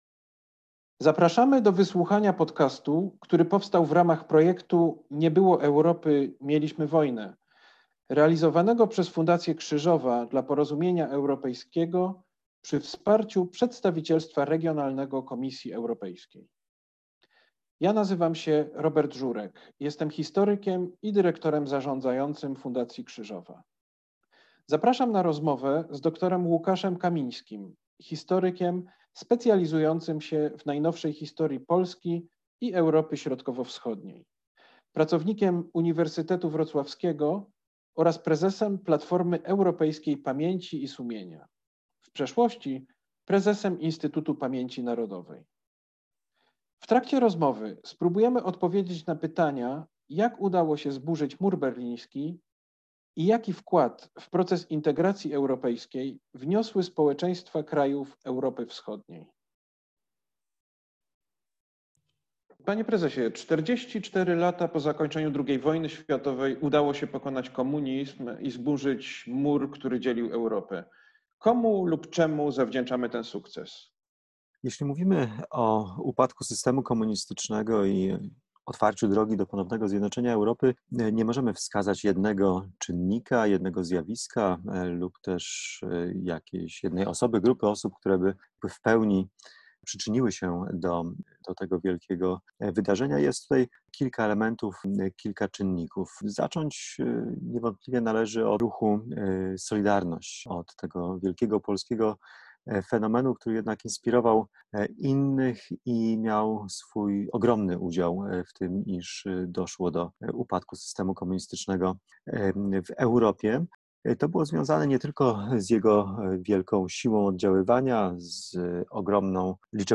Rozmowa z ekspertem: dr Łukasz Kamiński